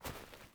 mining sounds